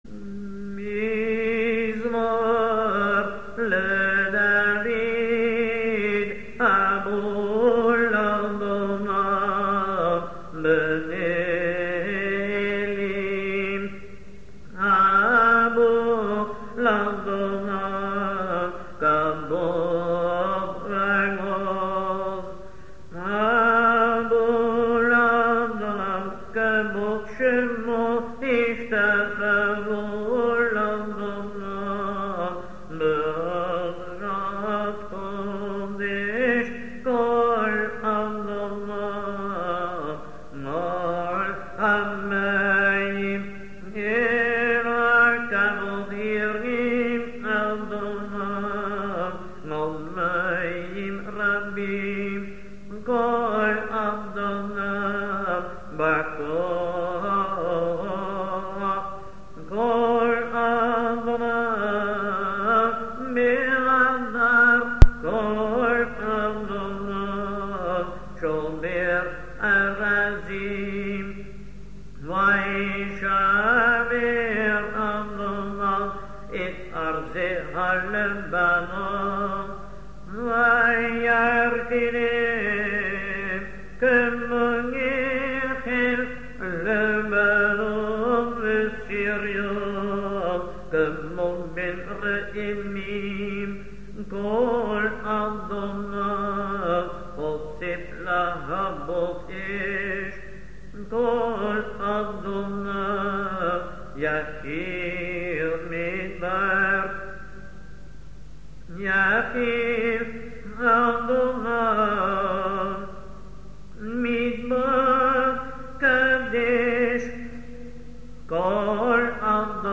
Psalm 29 is sung in unison on the melody used when returning the Sefer to the Hechal on shabbat.